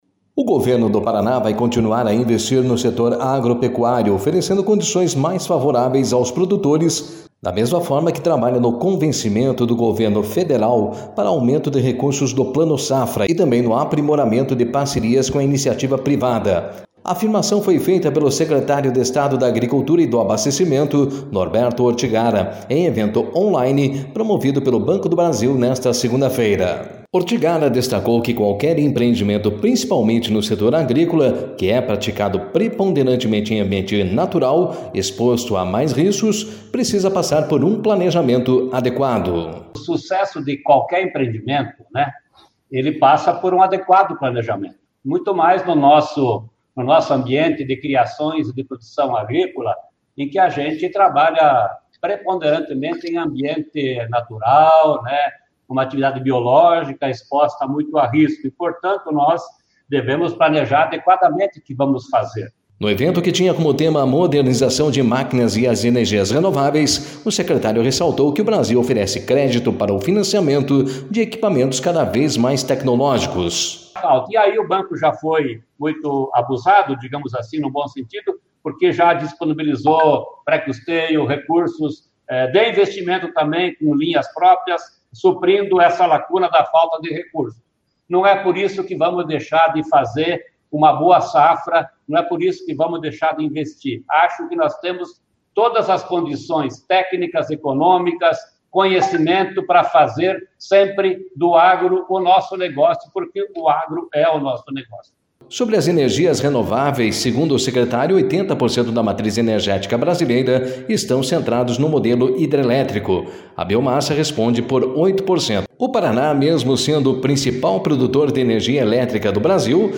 A afirmação foi feita pelo secretário de Estado da Agricultura e do Abastecimento, Norberto Ortigara, em evento online promovido pelo Banco do Brasil nesta segunda-feira.
// No evento, que tinha como tema a modernização de máquinas e as energias renováveis, o secretário ressaltou que o Brasil oferece crédito para o financiamento de equipamentos cada vez mais tecnológicos.